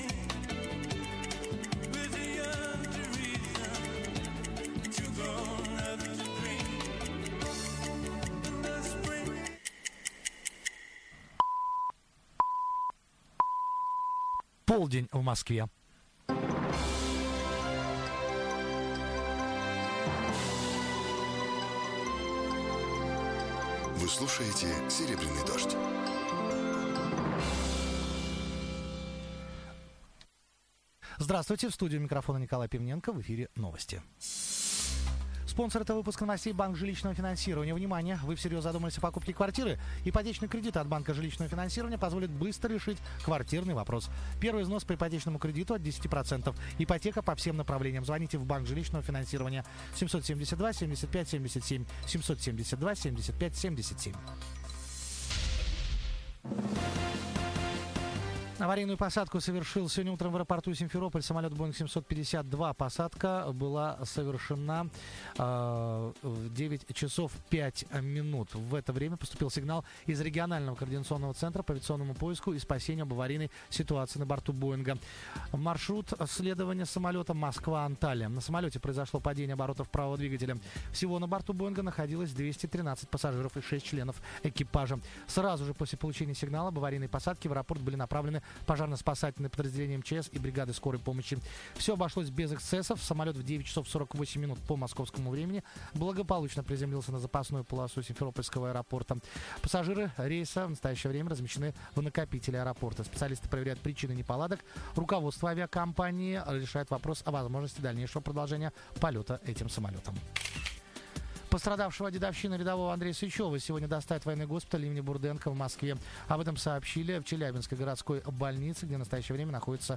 Новости (не до конца) (Серебряный дождь, 07.02.2006)